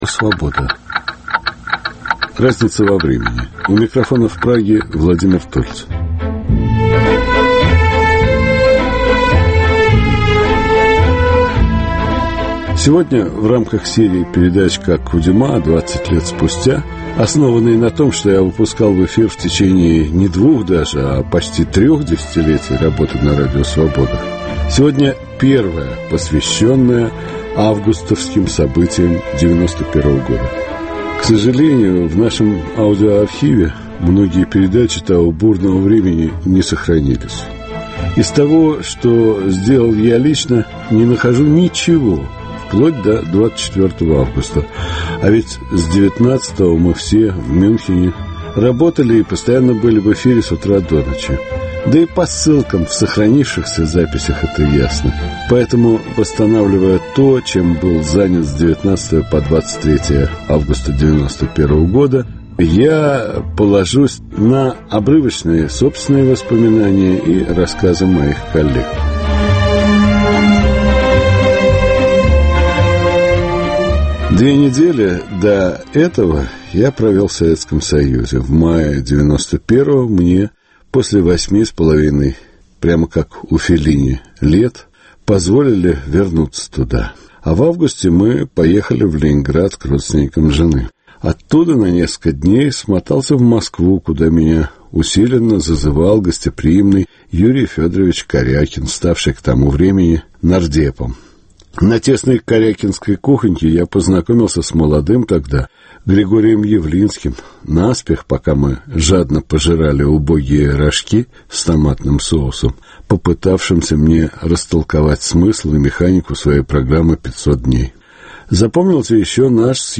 Первая часовая передача из цикла "Путч.- 20 лет спустя", основанного на записях 1991 г. и нынешних интервью с участниками прямого эфира Радио Свобода 19-21 августа 1991 г.